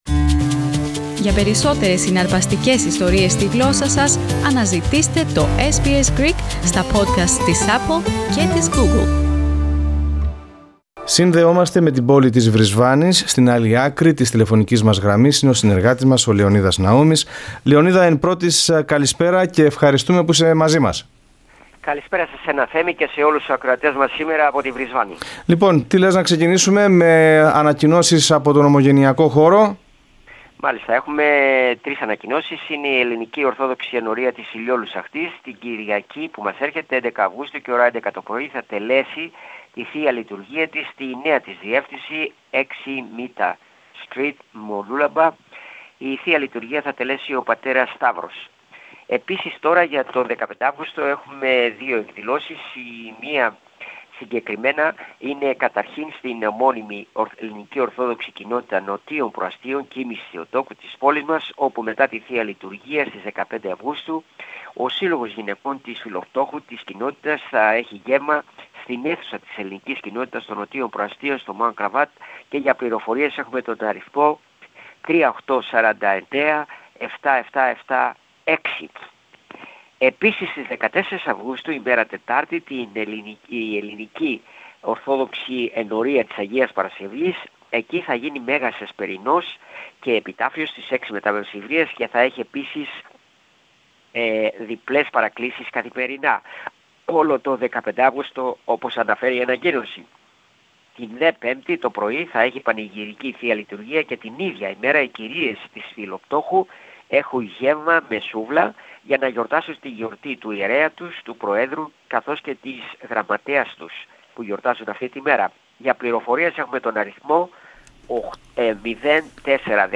reports from Queensland